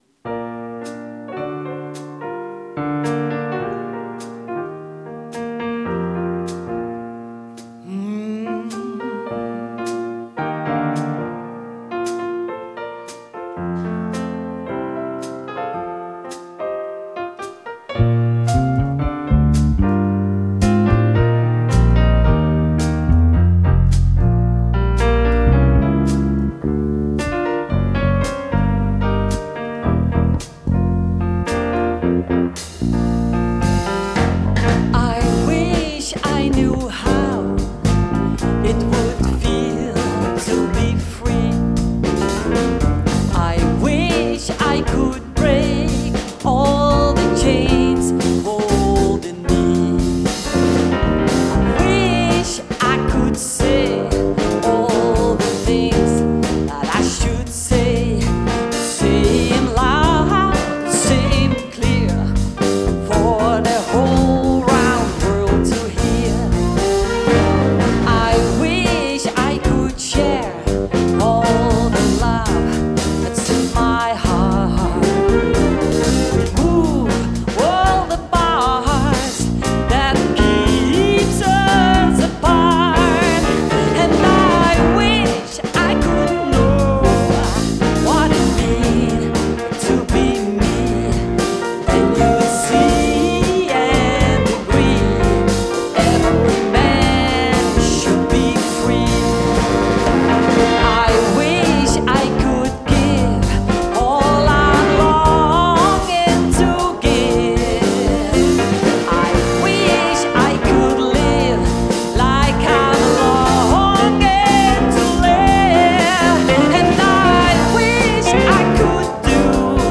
Genre: Big Band.